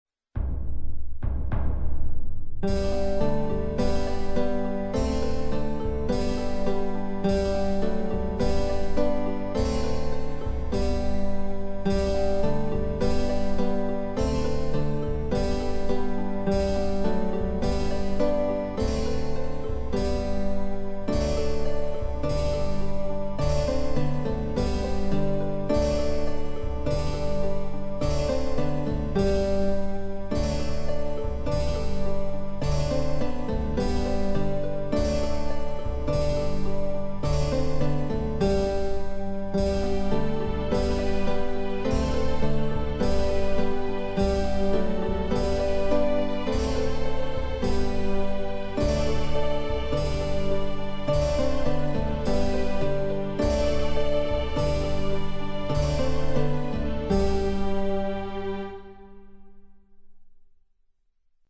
Proponiamo una Danza di Michael Praetorius, insigne teorico e organista tedesco, qui trascritta per due flauti.